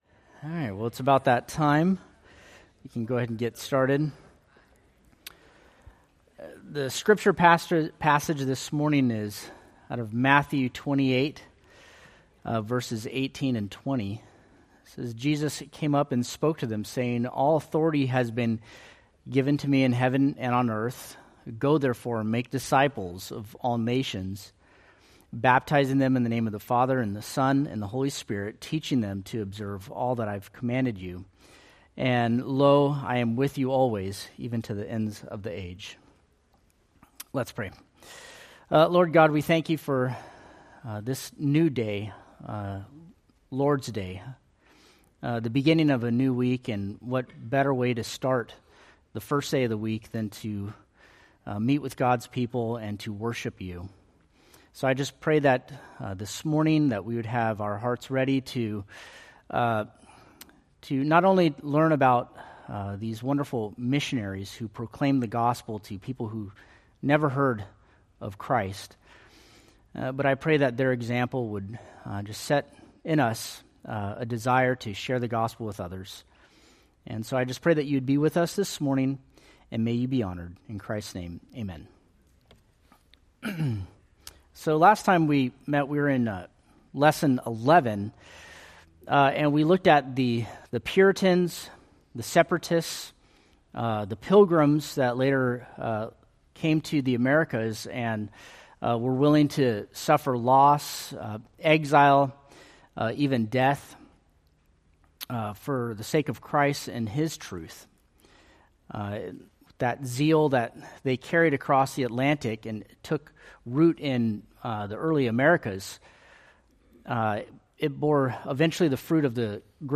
Date: May 25, 2025 Series: Forerunners of the Faith Grouping: Sunday School (Adult) More: Download MP3